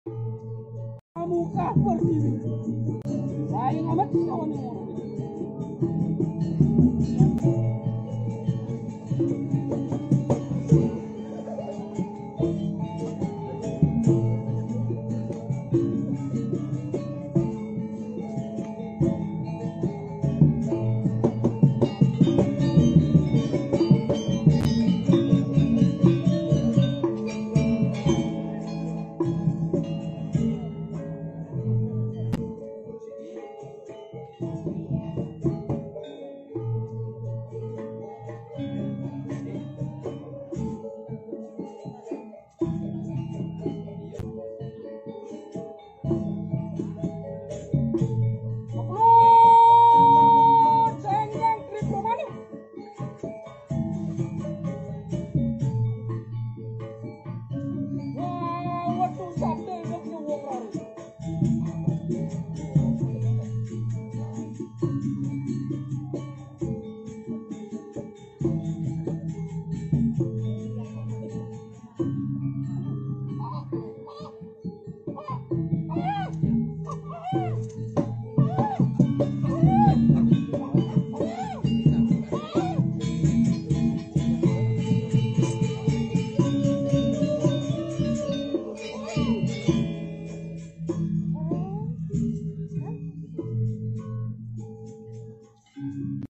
Ratu Rarung melakonkan adegan Anyawa Lare saat pementasan calonarang di Desa Padangbai, Anyawa Lare adalah adegan yang menceritakan seorang penekun ilmu leak yang sedang menghidupkan bayi di kuburan, Ratu Rarung adalah salahsatu topeng sakral berusia ratusan tahun yang disucikan di Desa Padangbai .